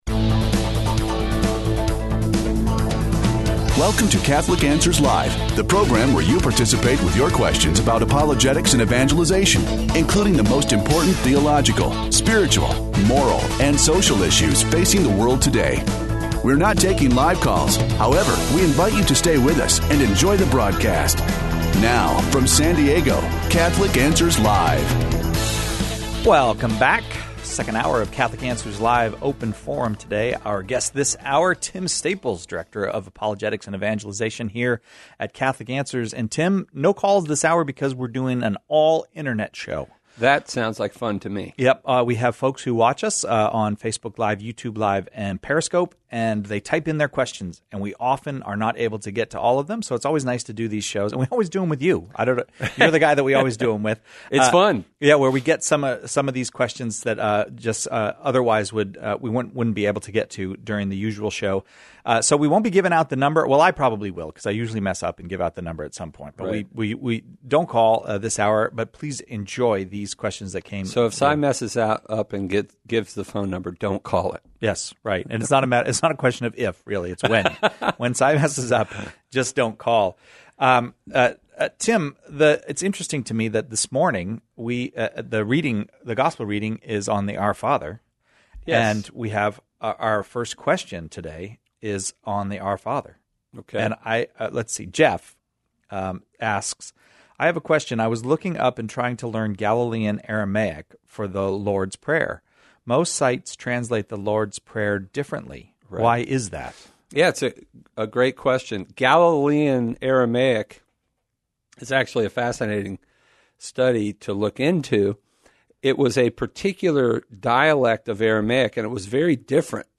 Open Forum (Pre-record)